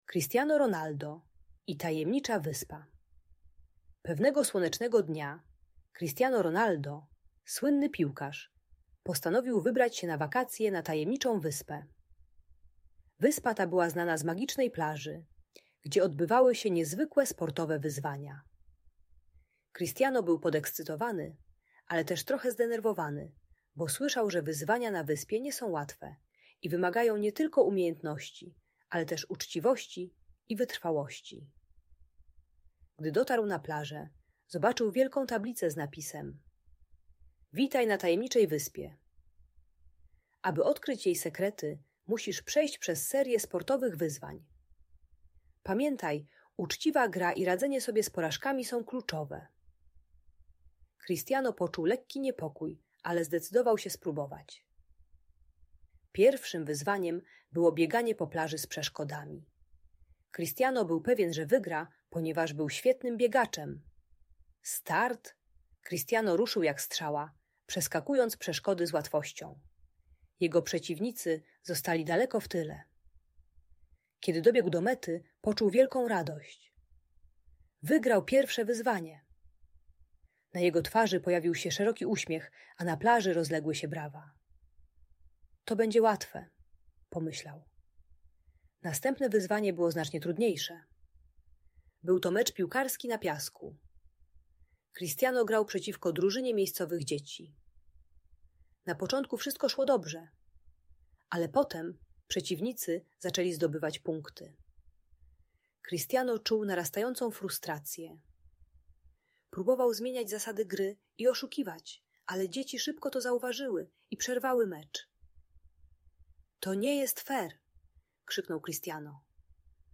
Cristiano Ronaldo i Tajemnicza Wyspa - Audiobajka dla dzieci